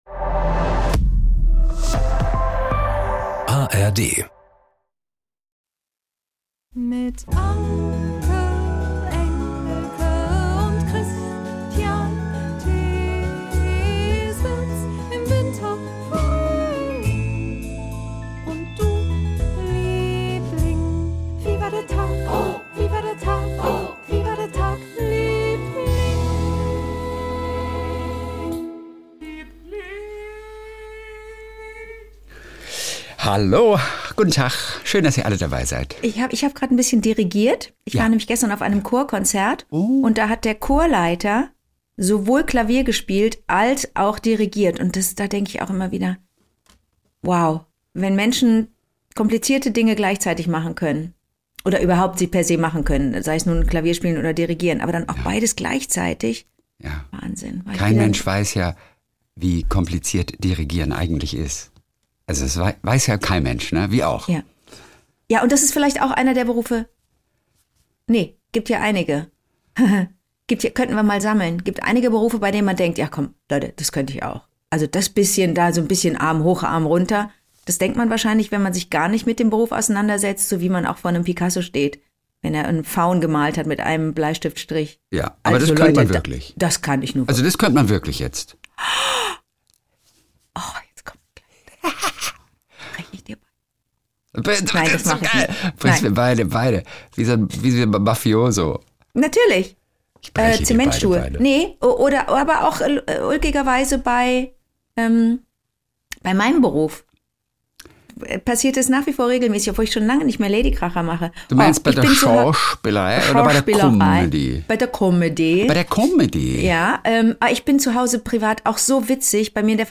Jeden Montag und Donnerstag Kult: SWR3-Moderator Kristian Thees ruft seine beste Freundin Anke Engelke an und die beiden erzählen sich gegenseitig ihre kleinen Geschichtchen des Tages.